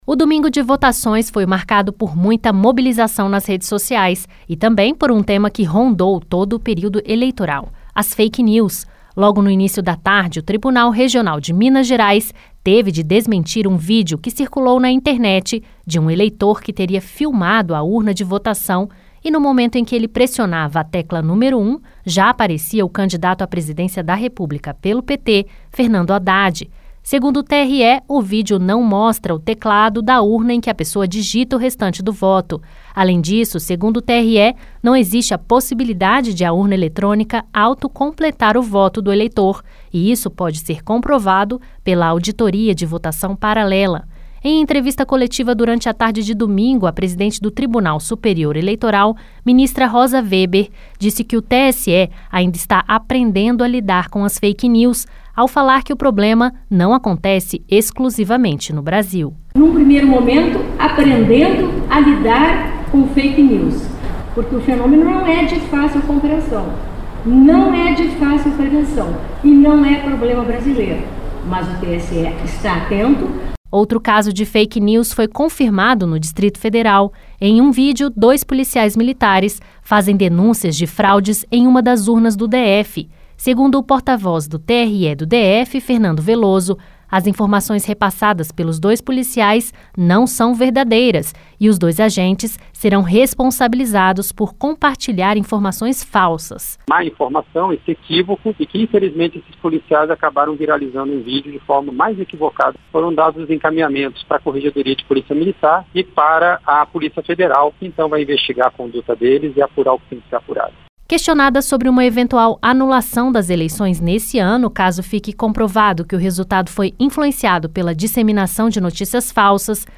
Os tribunais regionais, em pelo menos quatro estados, tiveram de verificar e desmentir informações repassadas pelas redes. A presidente do TSE, ministra Rosa Weber comentou o desafio que é combater esse problema.